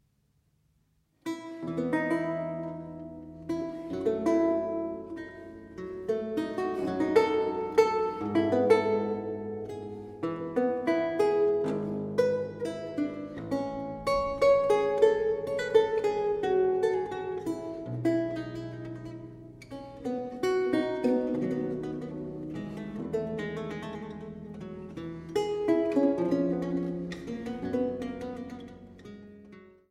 Laute